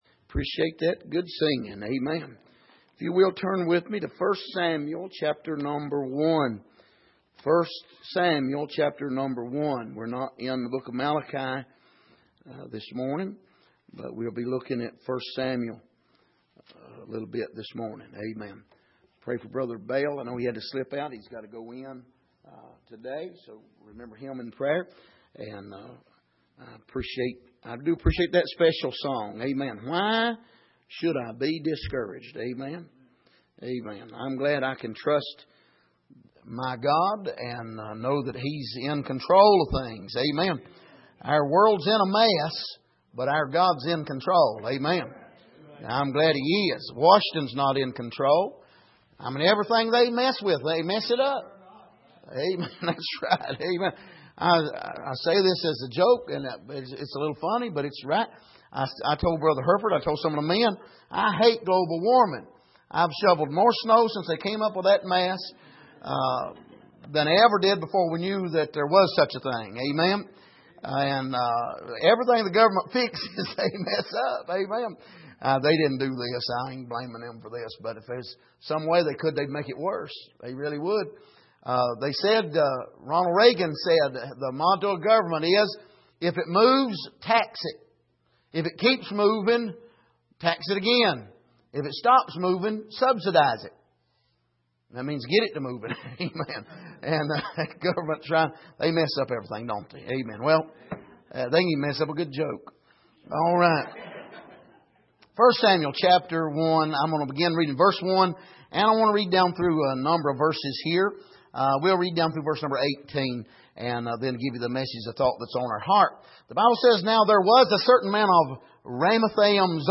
Miscellaneous Passage: 1 Samuel 1:1-19 Service: Midweek